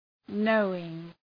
Προφορά
{‘nəʋıŋ}